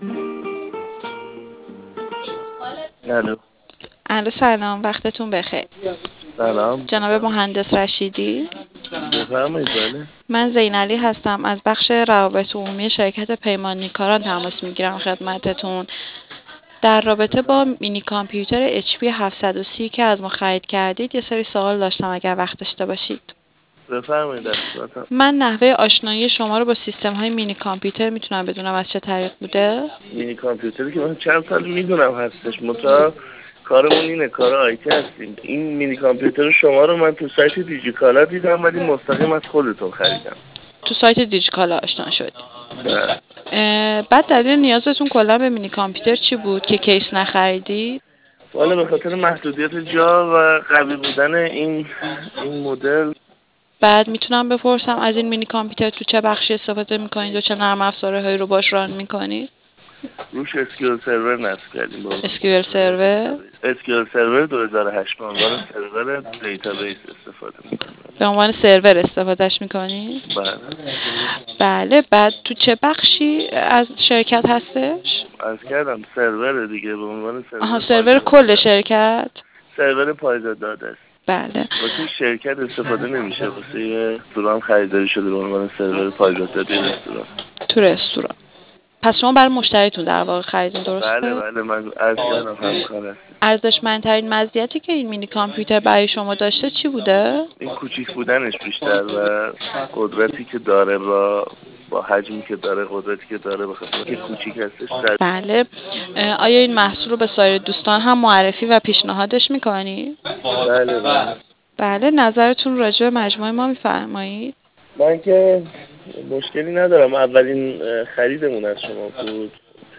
بدین منظور تعدادی مصاحبه با مشتریان عزیزمان که از مینی کامپیوتر استفاده کرده اند، گردآوری شده است.